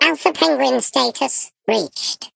Sfx_tool_spypenguin_vo_love_03.ogg